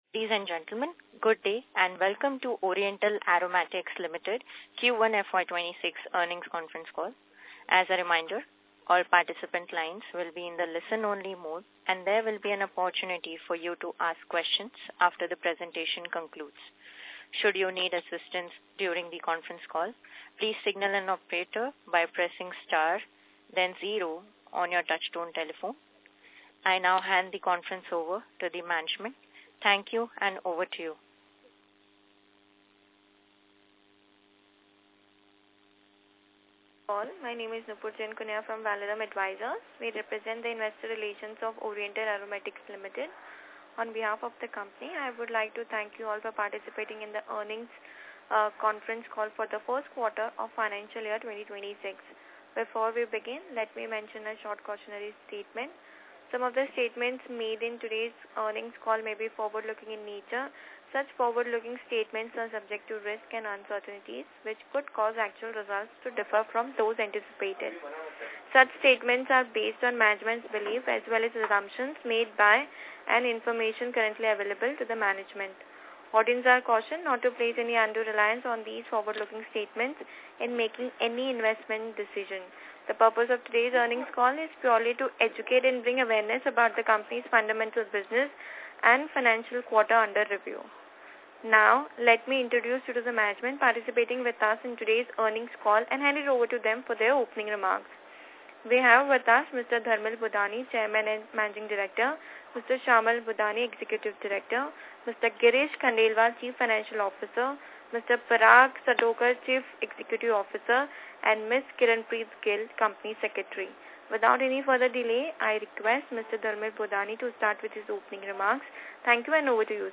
Concalls
Concall-OrientalAromaticsLtd-Q125-26.mp3